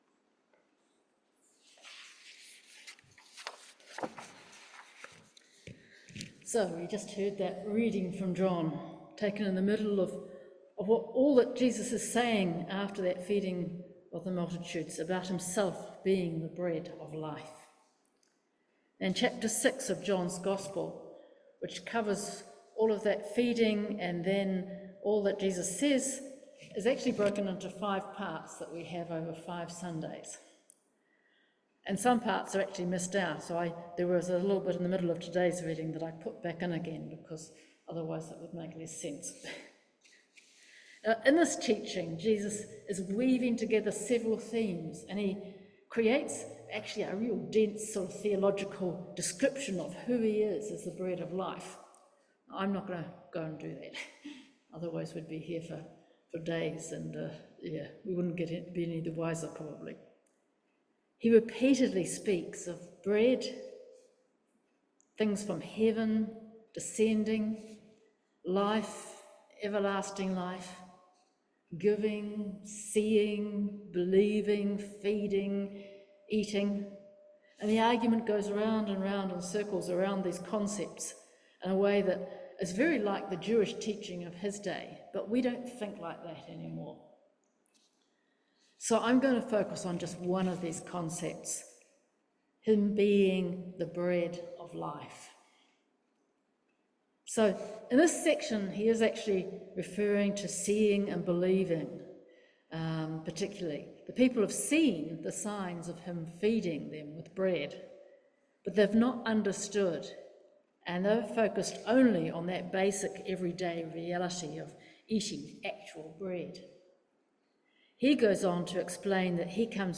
5:2 Service Type: Morning Worship How do we eat the Bread of Life?